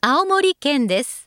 ホームページ作成で利用できる、さまざまな文章や単語を、プロナレーターがナレーション録音しています。